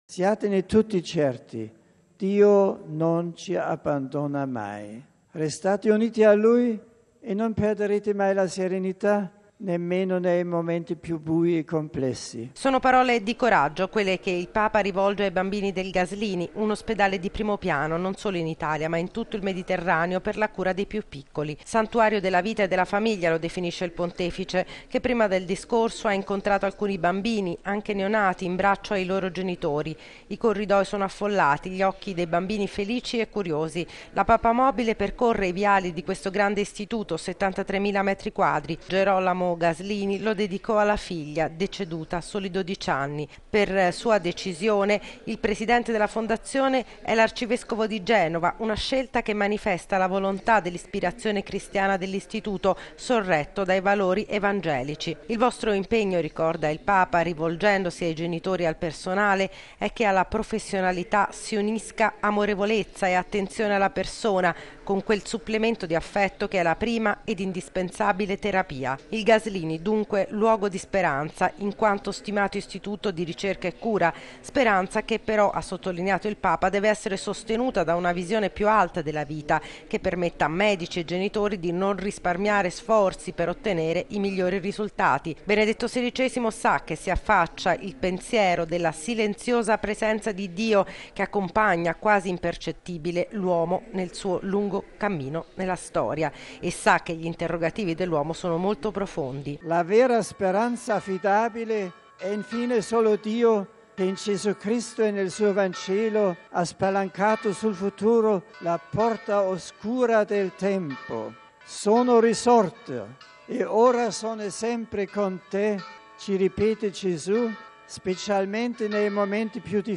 Il servizio della nostra inviata